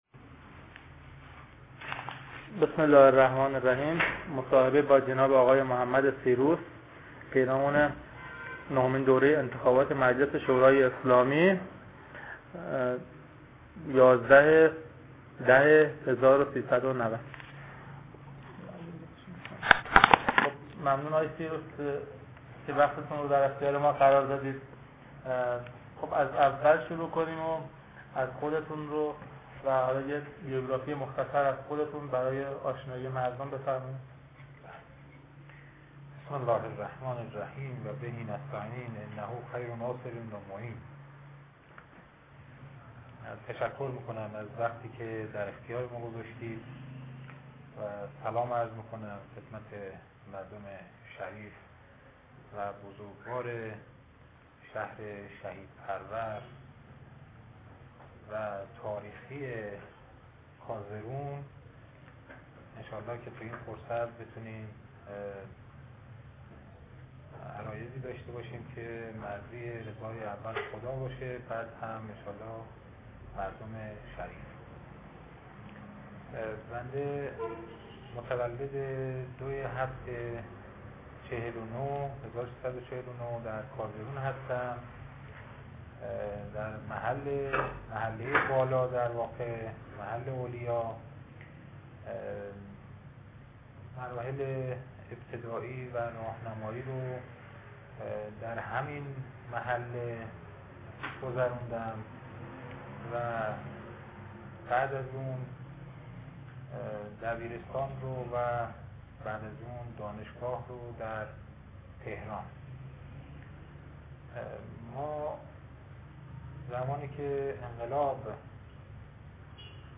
مصاحبه اختصاصی